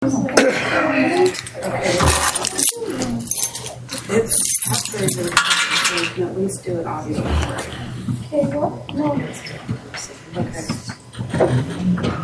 Board Meeting
The North Tooele Fire Protection Service District will hold a board meeting on November 20, 2024 at 6:30 p.m. at the Stansbury Park Fire Station, 179 Country Club, Stansbury Park, UT, 84074.